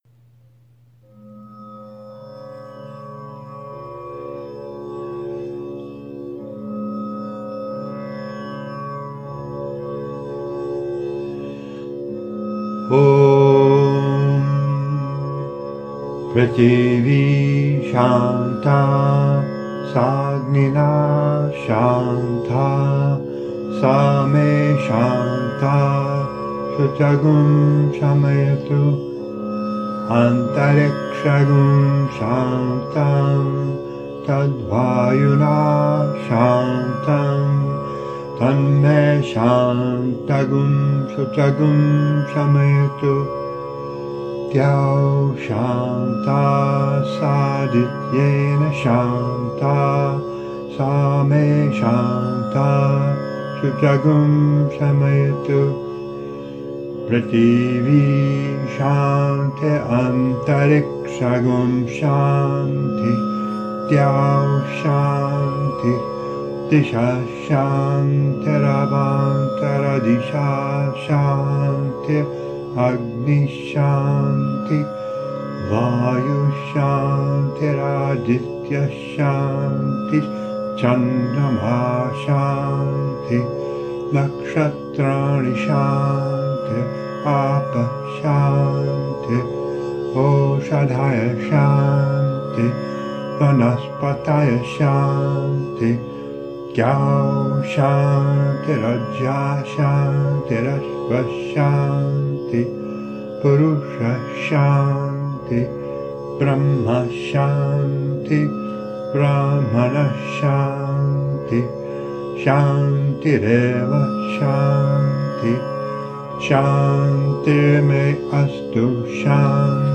mahāśāntiḥ mantra sanskrit recitation